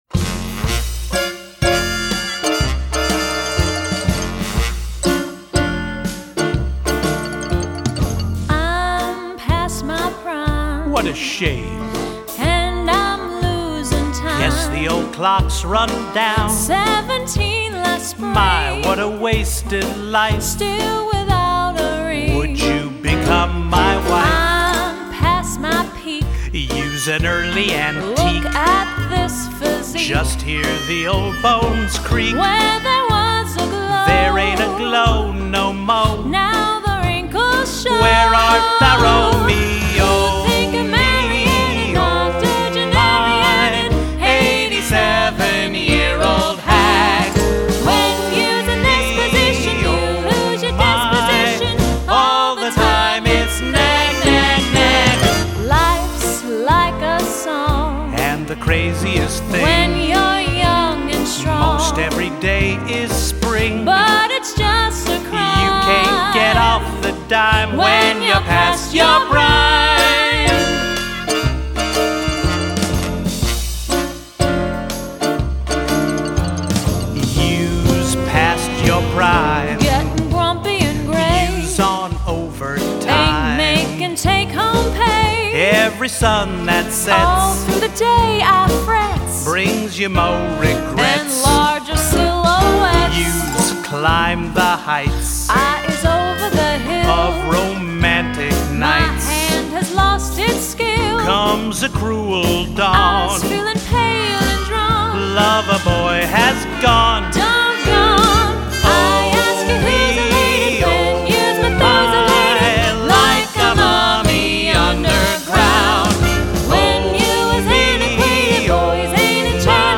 one of my all-time favorite musical theater songs